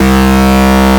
BEEF BUZZ.wav